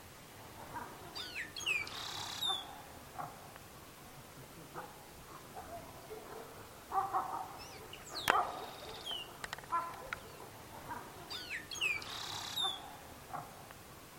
Tordo Patagónico (Curaeus curaeus)
Nombre en inglés: Austral Blackbird
Localización detallada: Camping Municipal
Condición: Silvestre
Certeza: Observada, Vocalización Grabada
Tordo-Patagonico.mp3